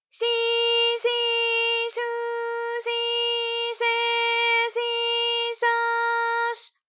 ALYS-DB-001-JPN - First Japanese UTAU vocal library of ALYS.
shi_shi_shu_shi_she_shi_sho_sh.wav